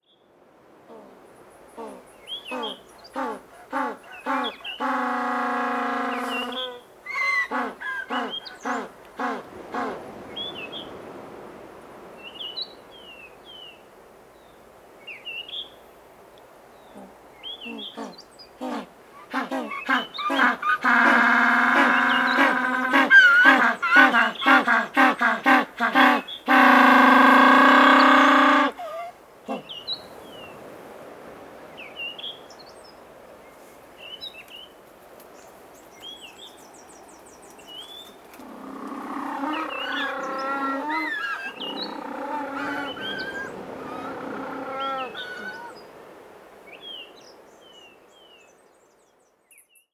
На этой странице вы найдете коллекцию звуков пингвинов: от их забавного «разговора» до шума шагов по льду.
Голосовые сигналы очкового пингвина